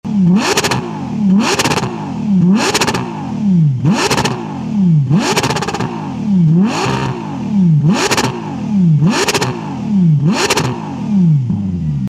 Capristo präsentiert Ihnen einen hochwertigen Endschalldämpfer mit Abgasklappen für den Ferrari Purosangue.
Purosangue-Straight-Pipe.mp3